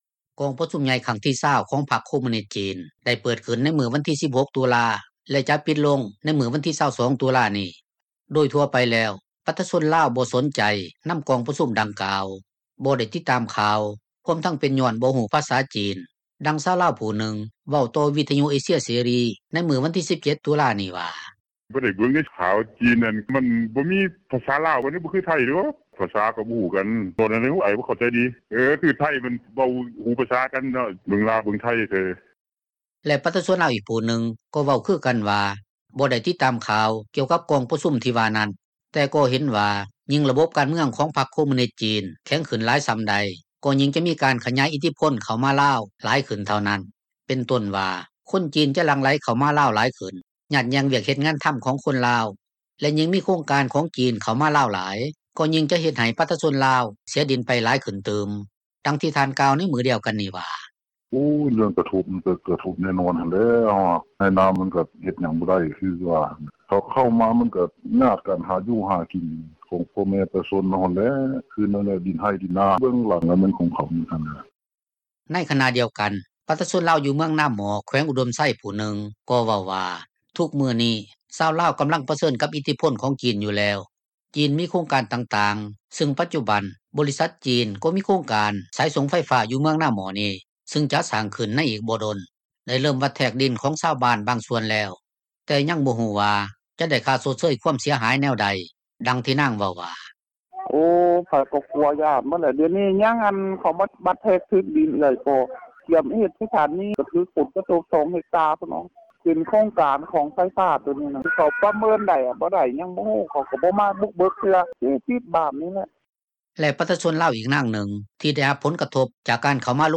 ດັ່ງຊາວລາວຜູ້ນຶ່ງ ເວົ້າຕໍ່ວິທຍຸ ເອເຊັຽເສຣີໃນມື້ວັນທີ 17 ຕຸລານີ້ວ່າ: